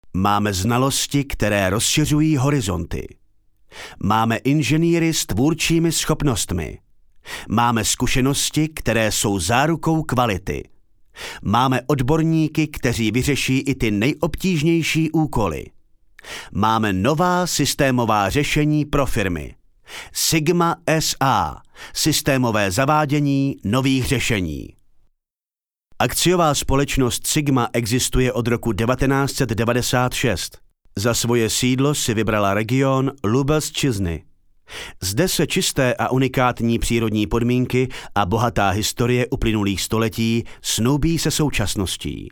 Sprecher tschechisch fĂŒr TV / Rundfunk / Industrie.
Kein Dialekt
Sprechprobe: eLearning (Muttersprache):
Professionell voice over artist from Czech.